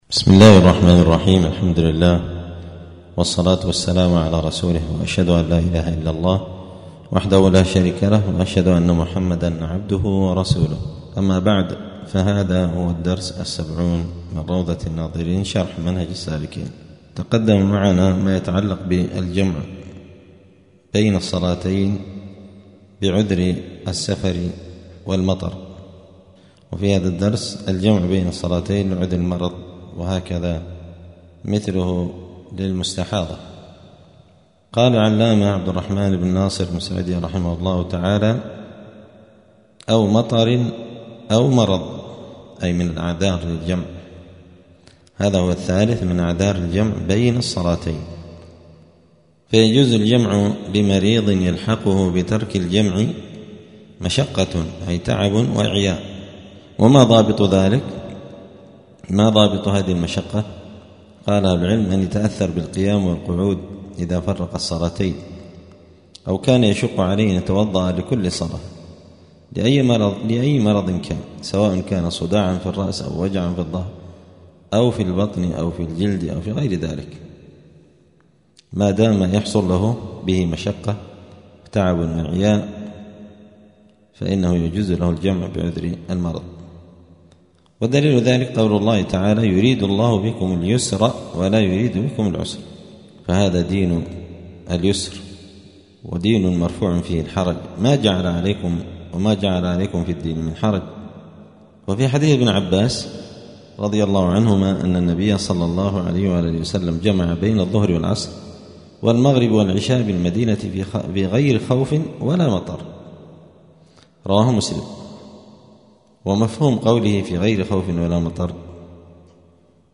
*الدرس السبعون (70) {كتاب الصلاة باب شروط الصلاة الجمع بين الصلاتين لعذر المرض}*